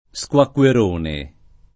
squacquerone [ S k U akk U er 1 ne ]